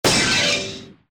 Bomb_1.mp3